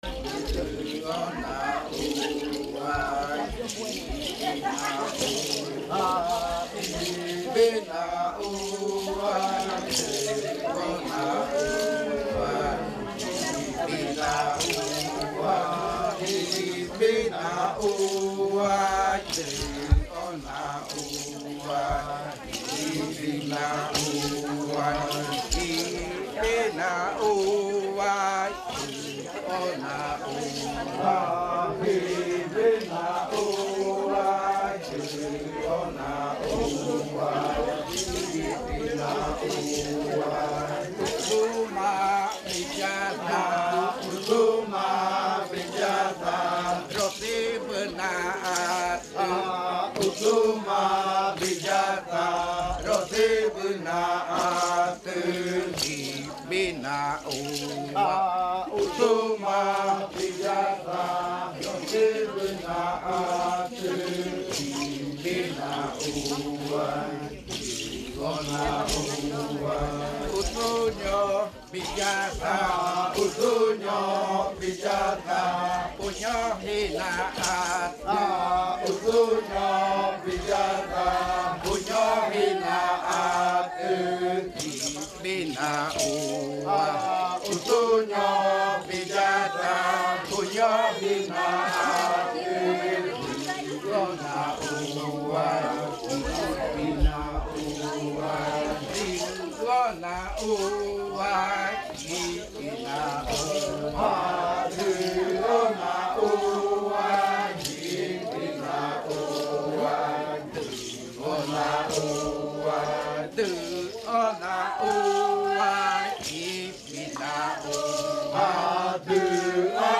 Canto de la variante muinakɨ
Leticia, Amazonas
con el grupo de cantores bailando en la Casa Hija Eetane. Este canto se interpretó en el baile de clausura de la Cátedra de Lenguas "La lengua es espíritu" de la UNAL, sede Amazonia.
with the group of singers dancing at Casa Hija Eetane. This song was performed at the closing dance ritual Language Lectureship “Language is Spirit”, of the UNAL Amazonia campus.